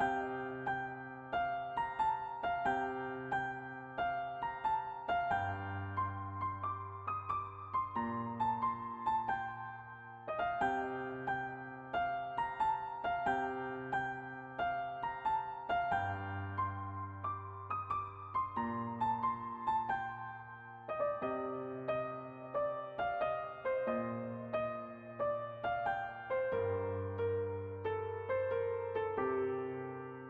Category: Tamil Ringtones